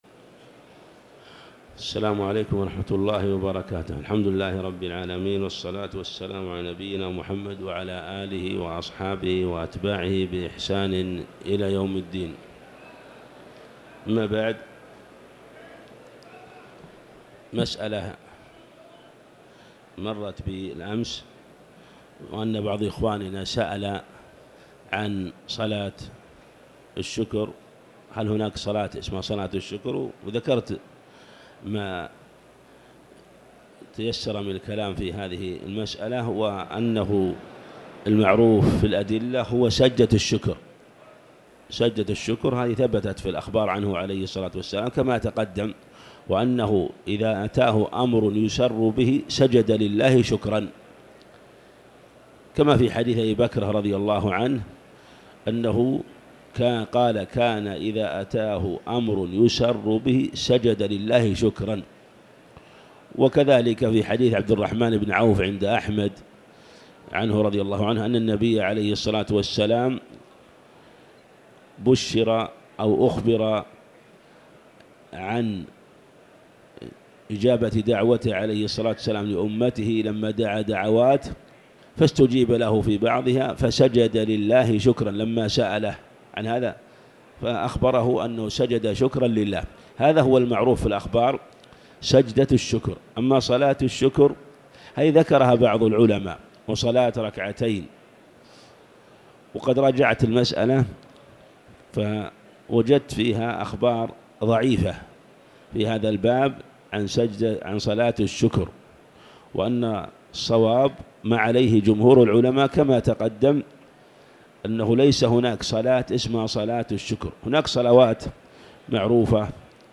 تاريخ النشر ١٧ رمضان ١٤٤٠ هـ المكان: المسجد الحرام الشيخ